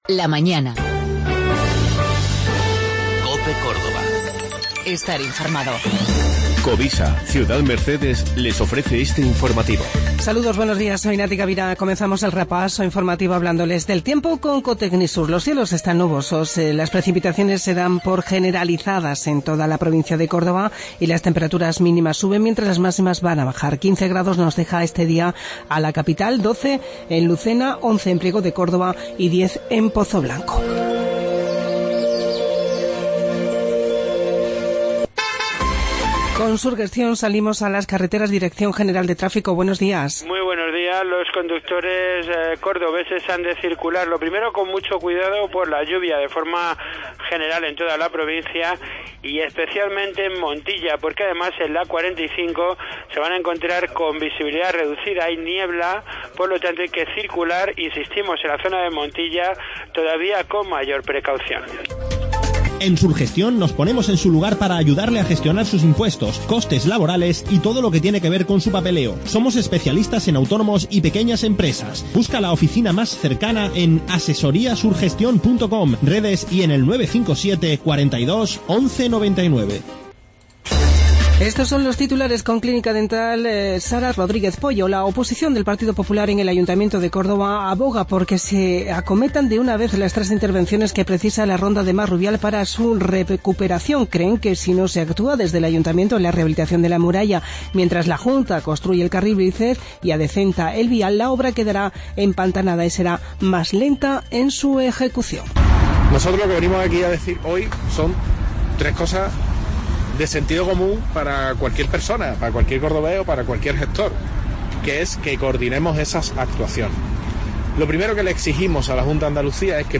informativo 13 de Febrero